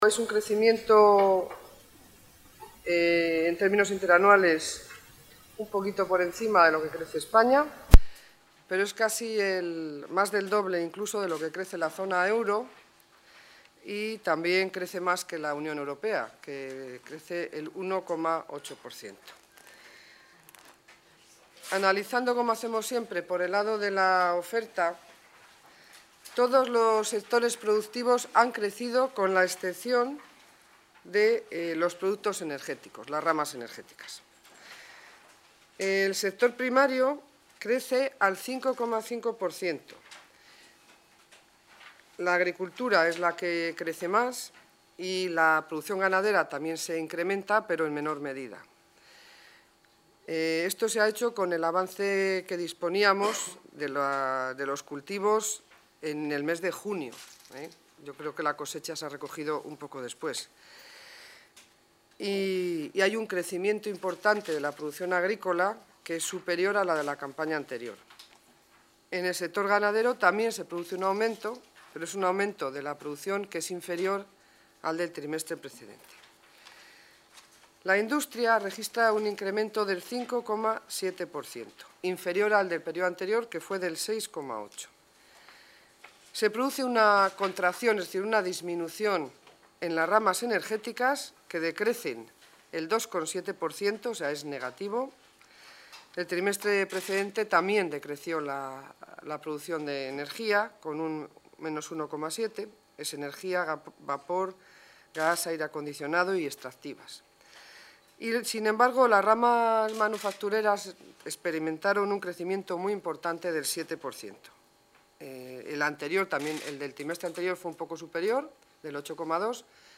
Audio consejera de Economía y Hacienda.